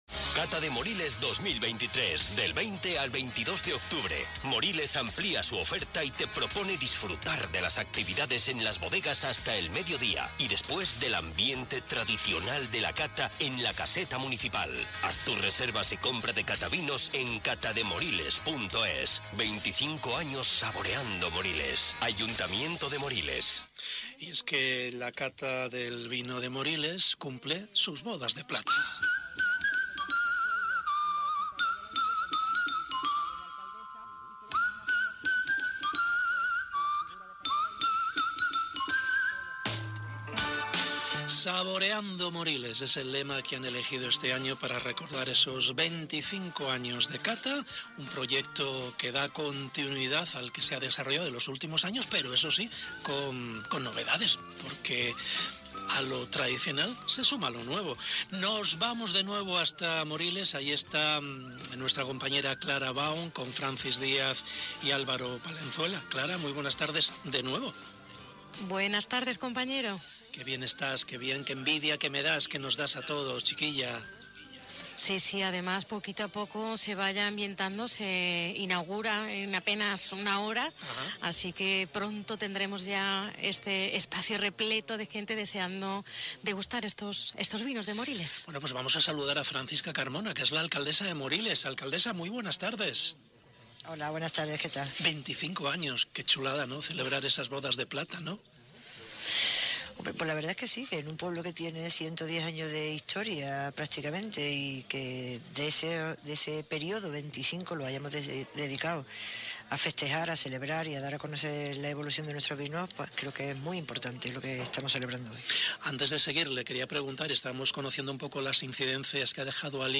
Directo | Cata del Vino de Moriles En Andalucía Centro hemos estado hoy, en directo, desde la Cata del Vino de Moriles hablando con todos los protagonistas de este evento referente del vino del sur de Córdoba.